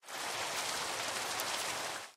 rain4.ogg